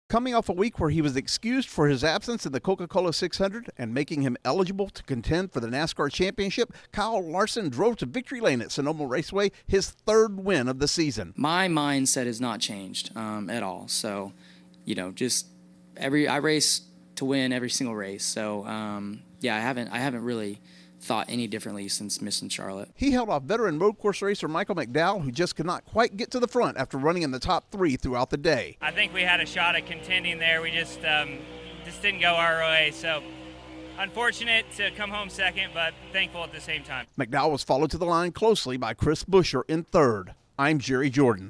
Kyle Larson wins in his home state. Correspondent